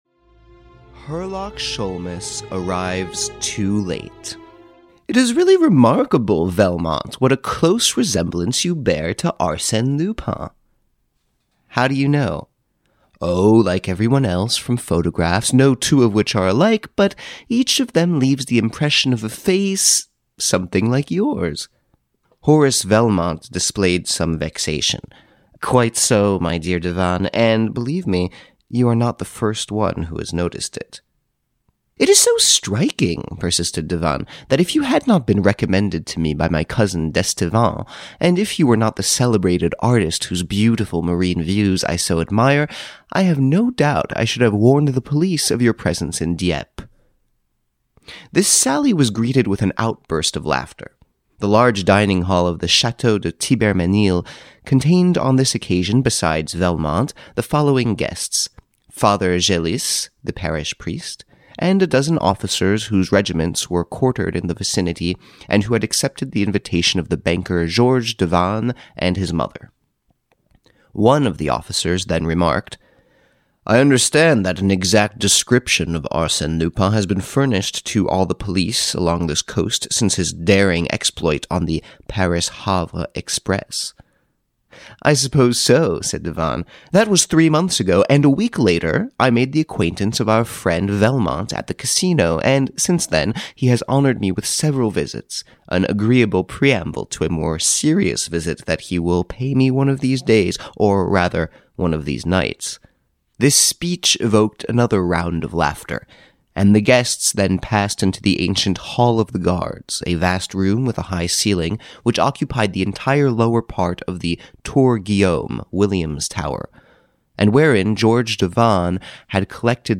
Herlock Sholmes Arrives Too Late, the Adventures of Arsène Lupin (EN) audiokniha
Ukázka z knihy